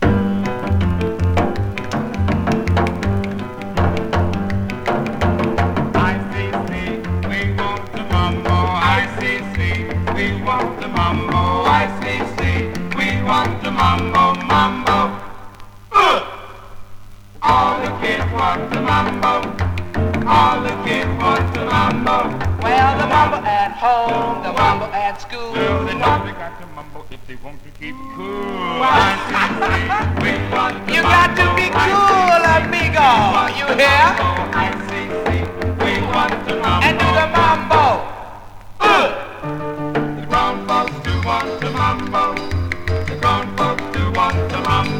Rhythm & Blues, Doo Wop　USA　12inchレコード　33rpm　Mono